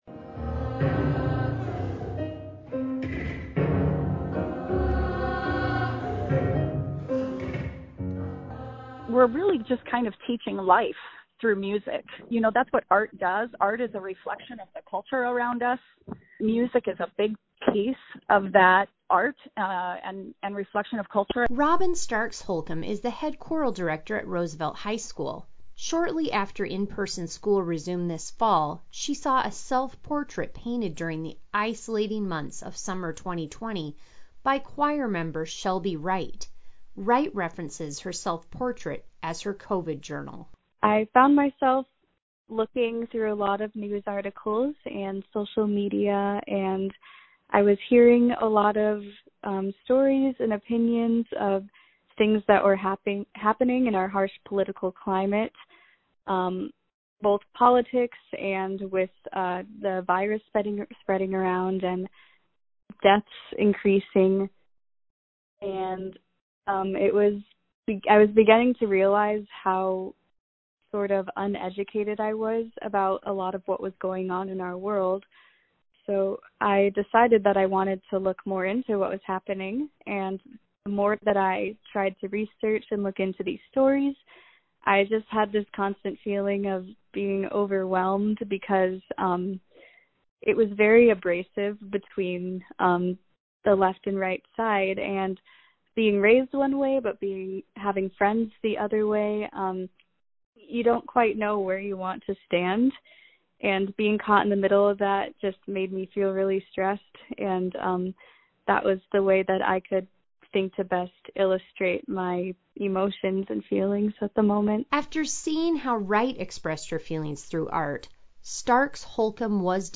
Throughout this story you have heard rehearsal clips of “Choked Air.”